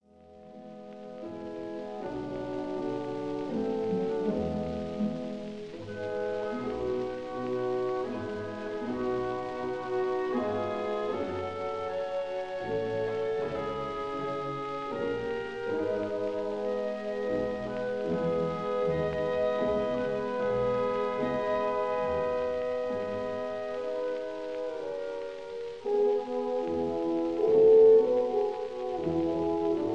Esta es una antigua grabación
hecha en el Kingsway Hall London